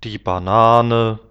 Banan (533x640)banán die Banane [bana:ne]
die-Banane.wav